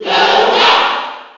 File:Dr. Mario Cheer NTSC SSB4.ogg
Dr._Mario_Cheer_NTSC_SSB4.ogg